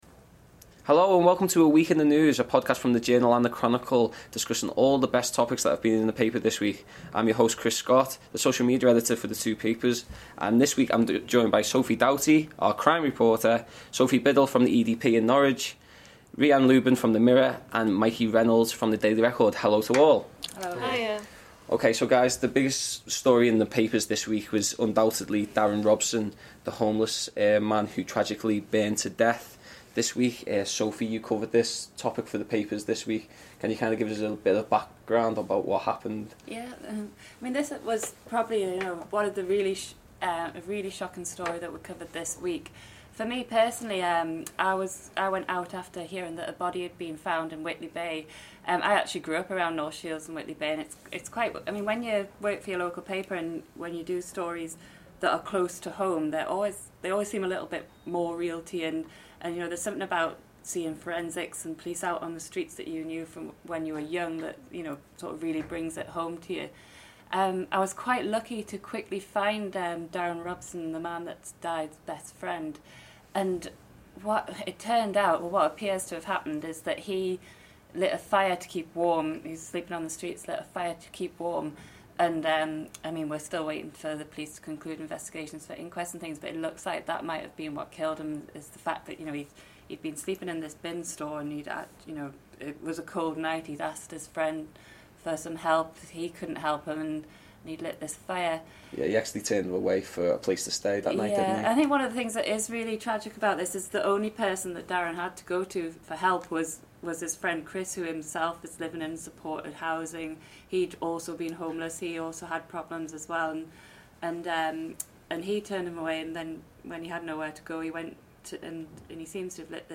Welcome to A Week in the News, a new podcast from The Chronicle & The Journal talking about the major news stories from the paper.